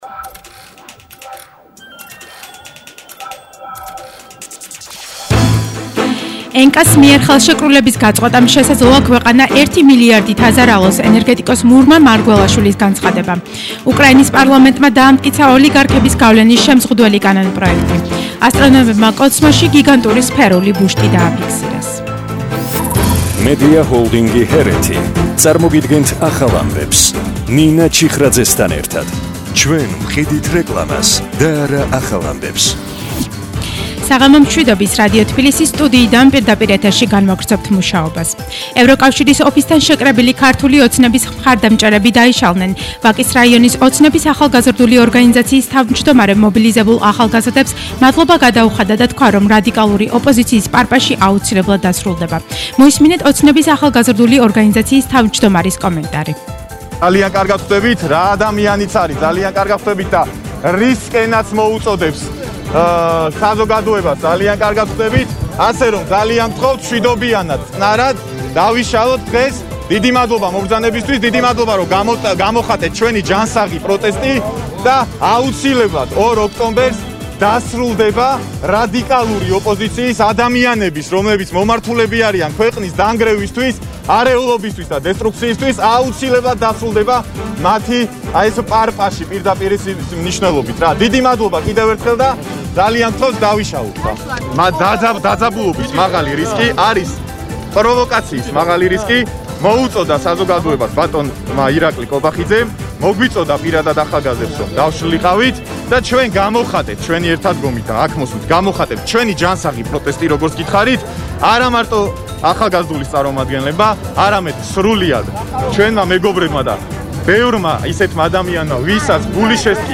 ახალი ამბები 19:00 საათზე –23/09/21